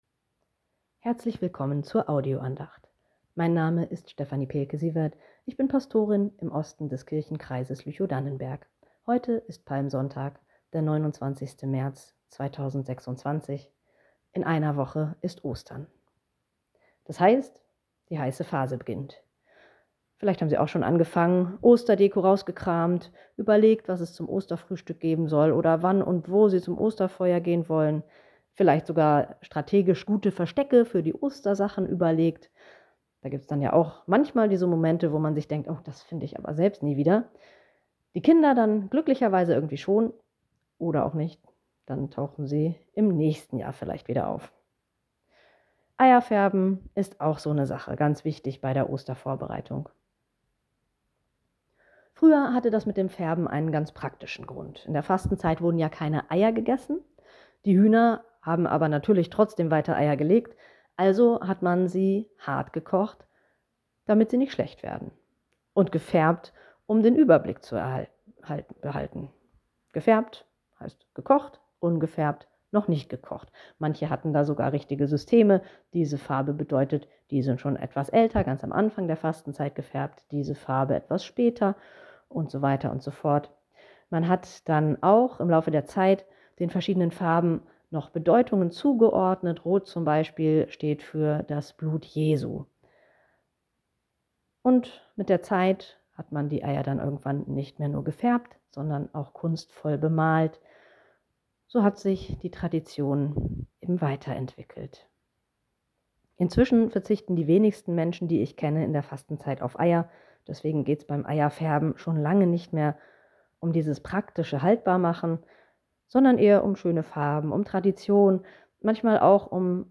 Telefon-Andachten des ev.-luth. Kirchenkreises Lüchow-Dannenberg